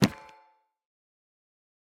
pickup_enchanted2.ogg